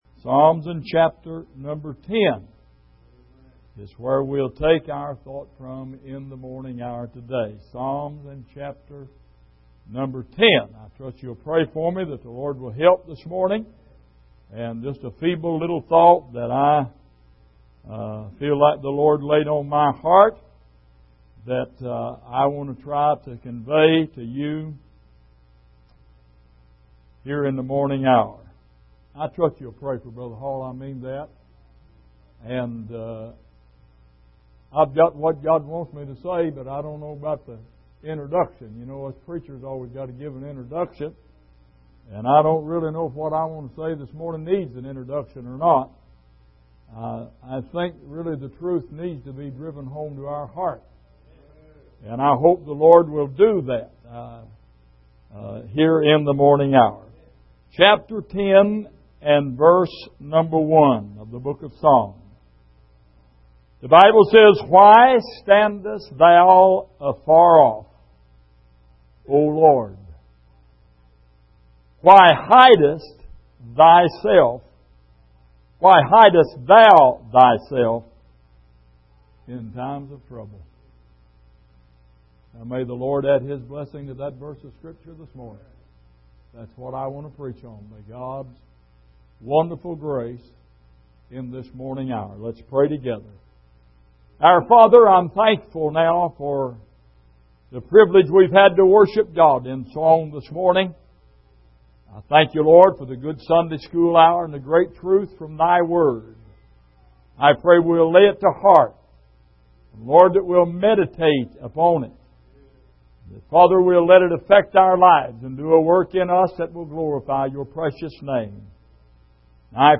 Exposition of the Psalms Passage: Psalm 10:1 Service: Sunday Morning Where Is The Lord When We Need Him The Most?